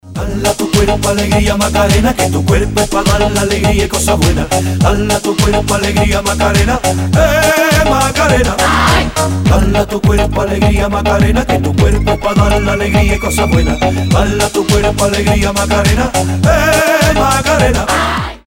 Танцевальные рингтоны
Зажигательные
Динамичные , Dance pop , Фламенко